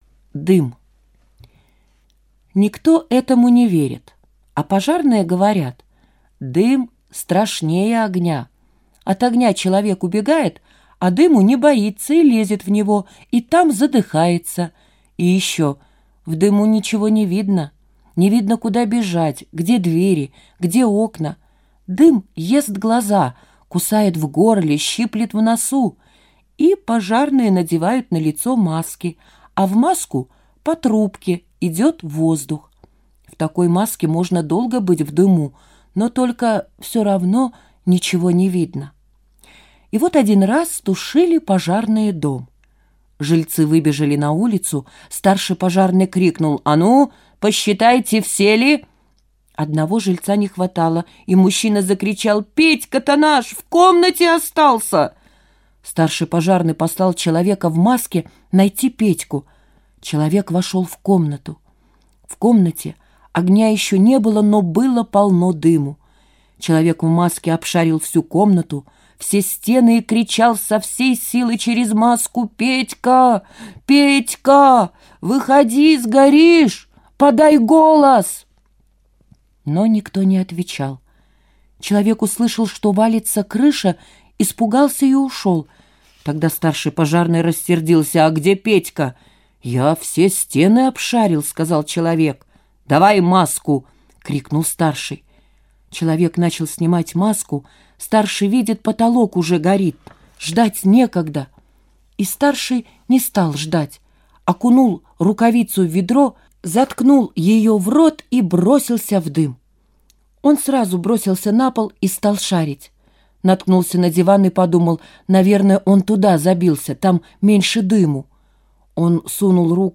Аудиорассказ «Дым»